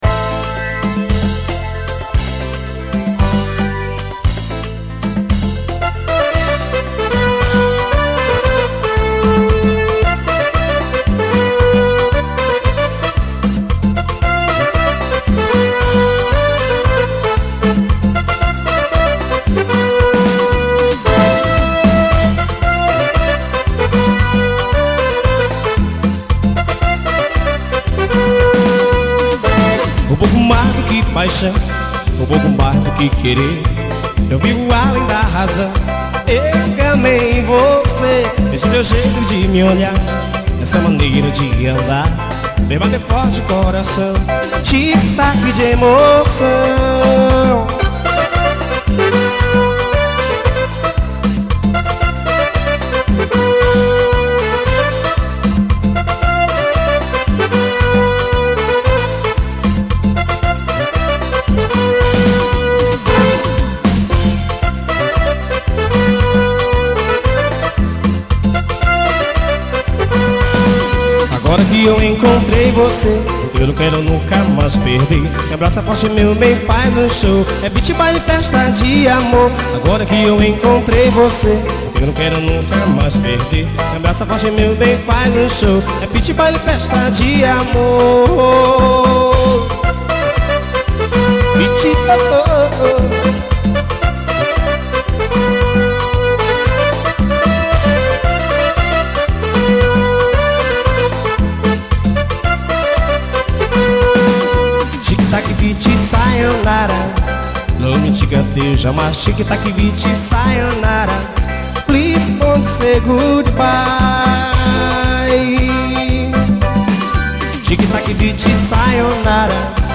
AO VIVO 2000.